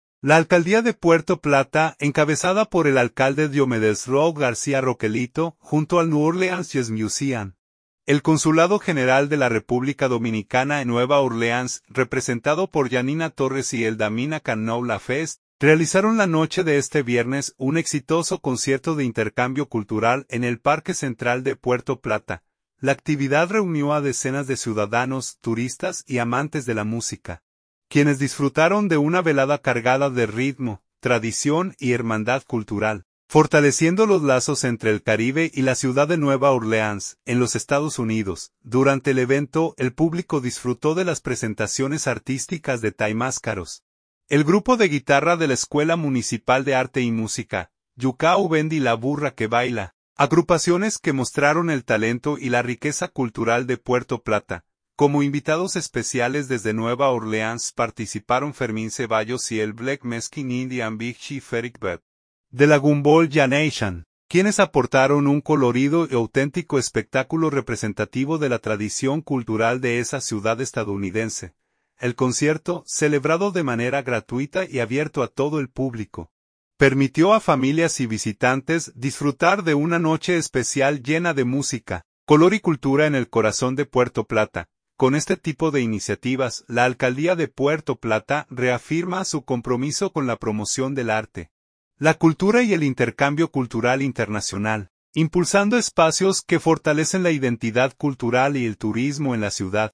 La actividad reunió a decenas de ciudadanos, turistas y amantes de la música, quienes disfrutaron de una velada cargada de ritmo, tradición y hermandad cultural, fortaleciendo los lazos entre el Caribe y la ciudad de Nueva Orleans, en los Estados Unidos.
Durante el evento, el público disfrutó de las presentaciones artísticas de Taimáscaros, el Grupo de Guitarra de la Escuela Municipal de Arte y Música, Yucahu Band y La Burra que Baila, agrupaciones que mostraron el talento y la riqueza cultural de Puerto Plata.
El concierto, celebrado de manera gratuita y abierto a todo el público, permitió a familias y visitantes disfrutar de una noche especial llena de música, color y cultura en el corazón de Puerto Plata.